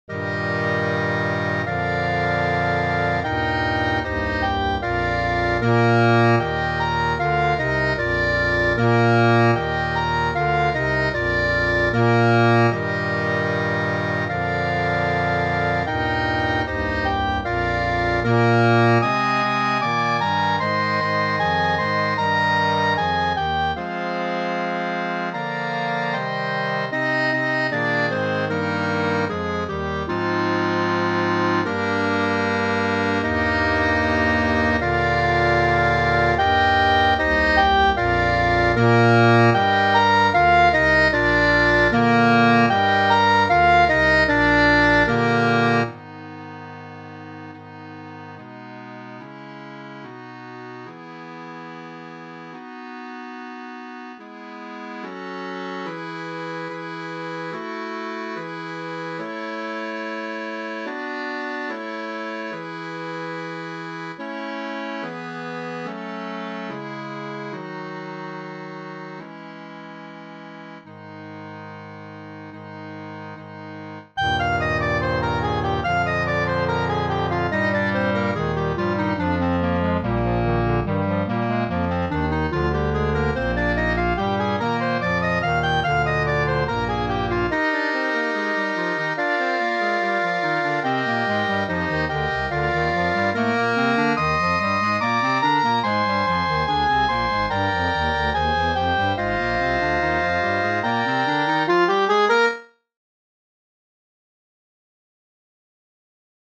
FOR CLARINET CHOIR